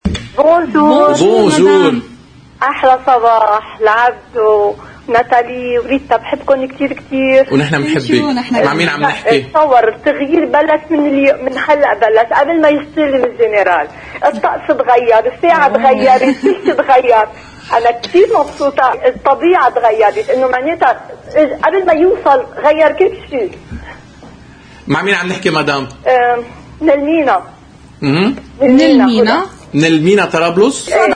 إتصلّت إحدى المشاهدات من منطقة المينا في طرابلس، بقناة الـ”OTV” لتقول كل شيء تغيّر قبل وصول العماد ميشال عون رئيساً للجمهورية،